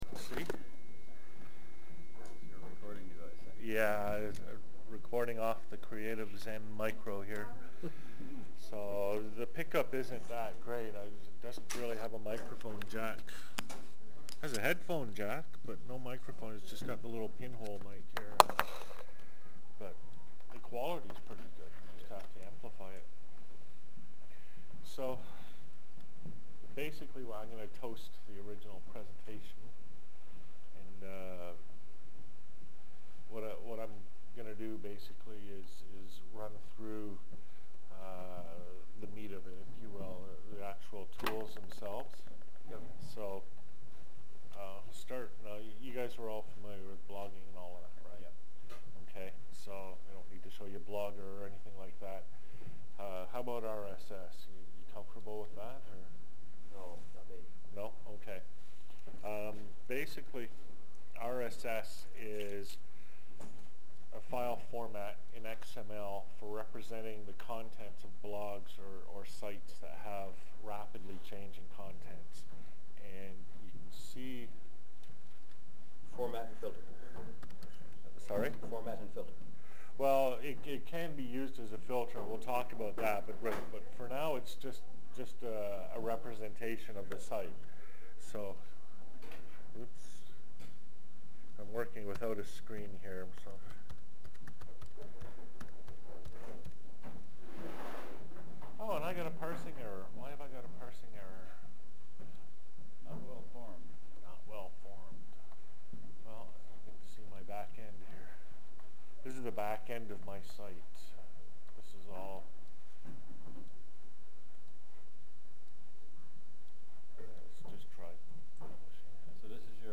Audio recording of a workshop in which I describe gRSShopper and how it has been developed to support OLDaily and similar initiatives.